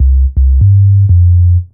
04SYN.LICK.wav